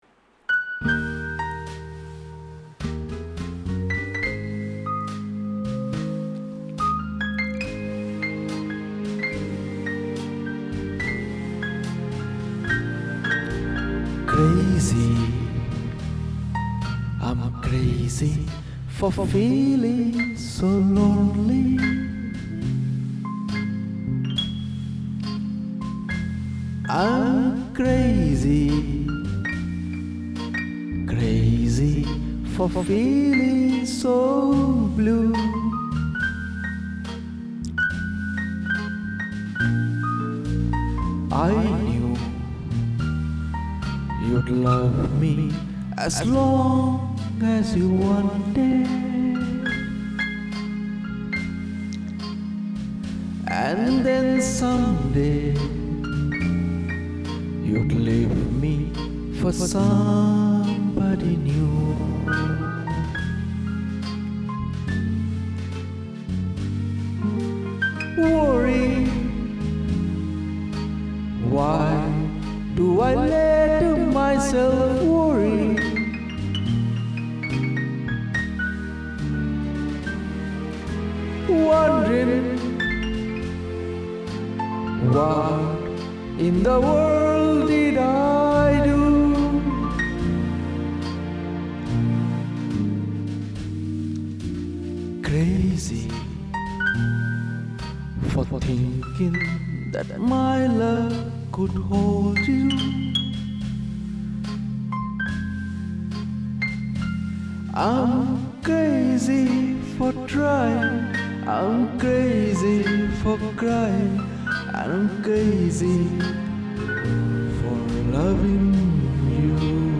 My home recording studio has configuration like this: Hardware: 1. M-Audio Fast Track Pro - USB 2. Beta Three - Digital Delay DD2 3. Behringer Large Diaphragm Studio Condenser Microphone C-3 Software: 1. Sony Acid Pro 7.0 2. Sony Sound Forge Pro 10.0 Procedure I follow: 1. I use 48kHz...